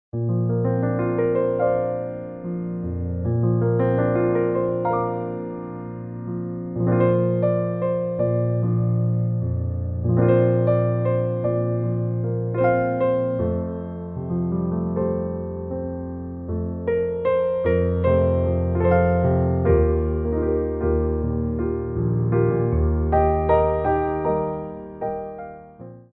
4/4 (8x8)